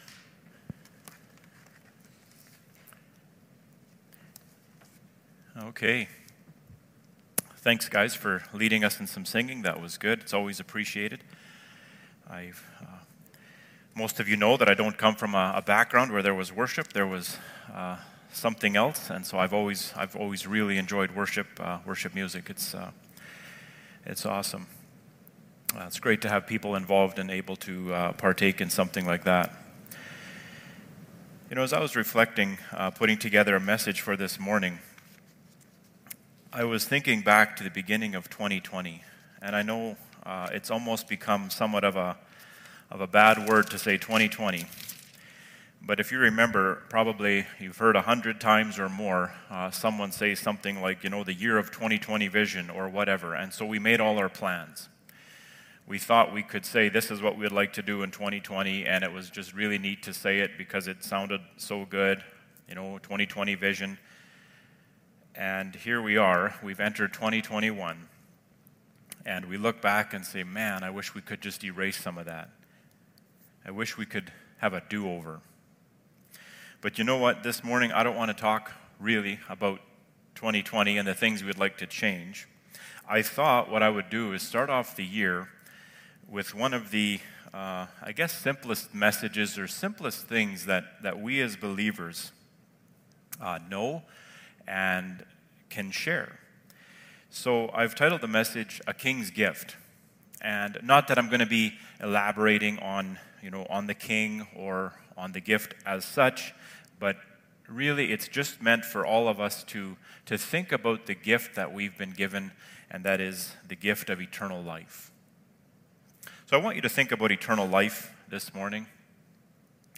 Sermons – Page 7 – Emmaus Bible Church
Service Type: Sunday Morning